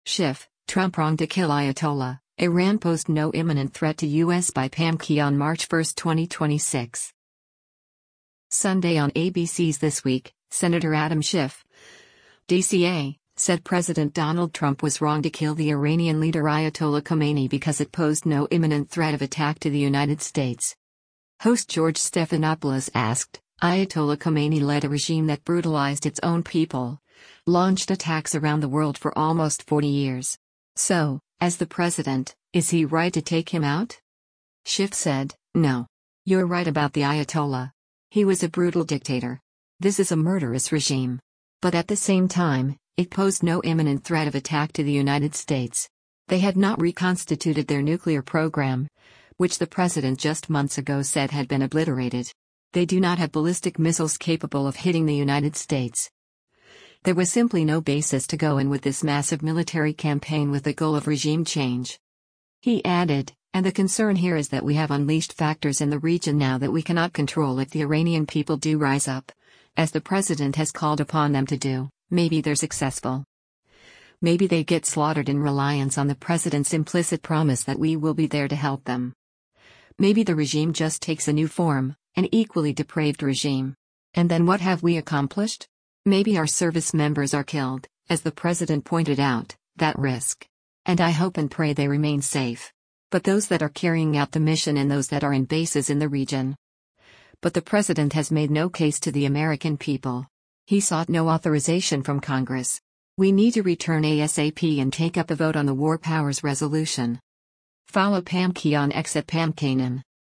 Host George Stephanopoulos asked, “Ayatollah Khomeini led a regime that brutalized its own people, launched attacks around the world for almost 40 years. So, as the president, is he right to take him out?”